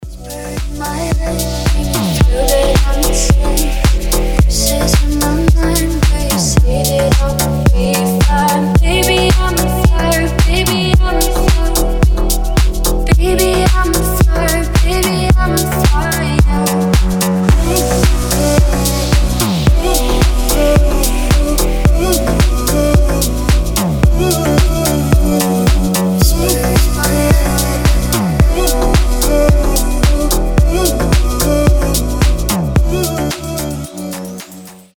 • Качество: 320, Stereo
deep house
мелодичные
красивый женский голос